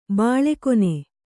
♪ bāḷe kone